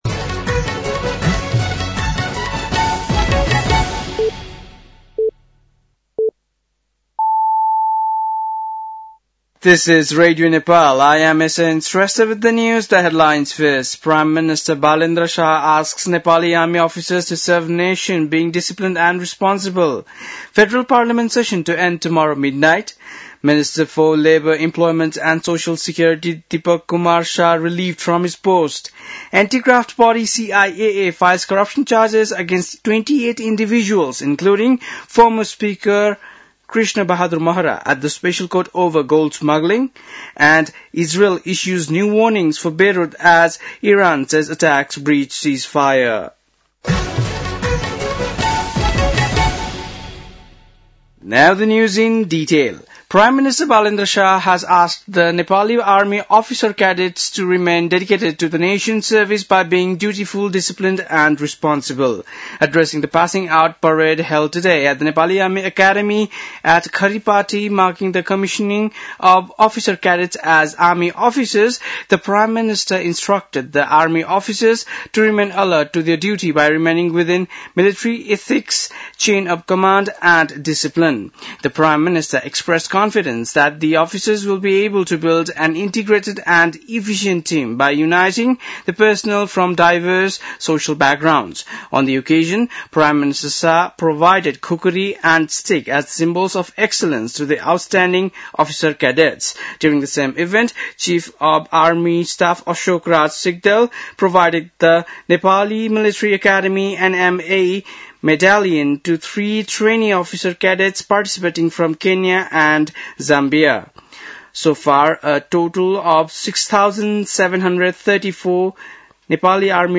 बेलुकी ८ बजेको अङ्ग्रेजी समाचार : २६ चैत , २०८२
8.-pm-english-news-.mp3